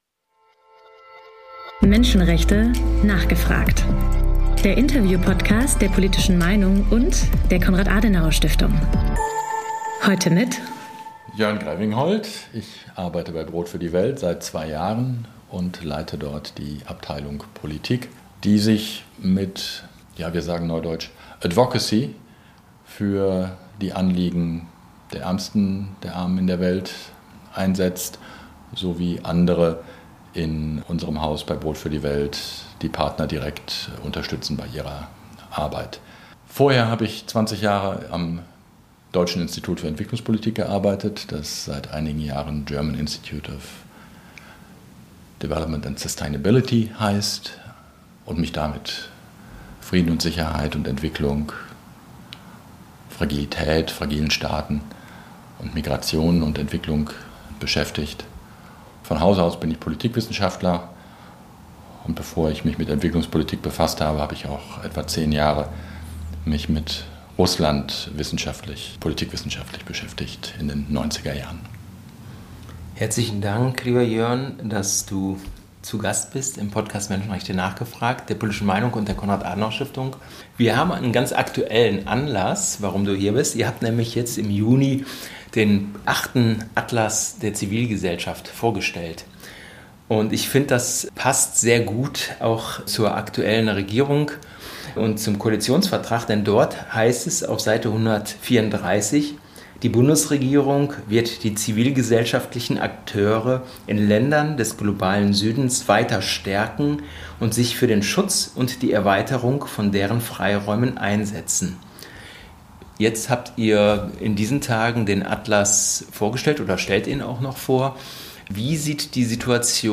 Menschenrechte: nachgefragt! - Der Interview-Podcast rund ums Thema Menschenrechte – Podcast